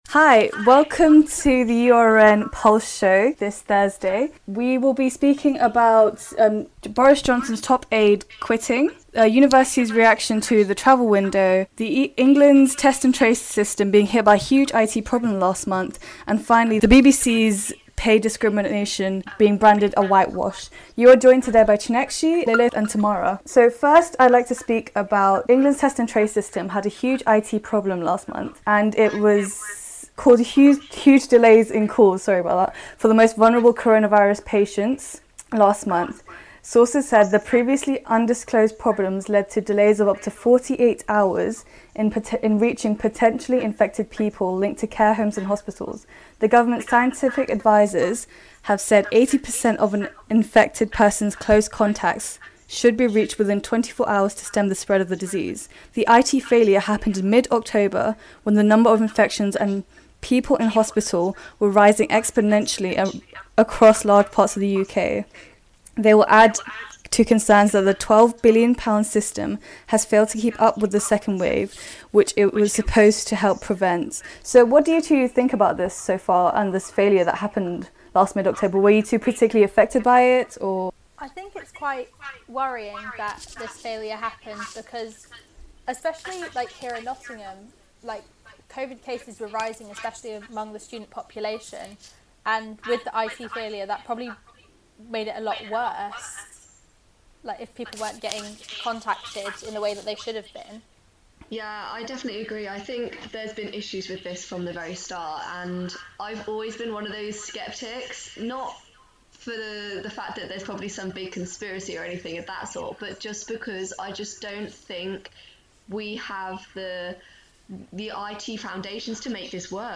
Join the Pulse team as they discuss the day's biggest news stories.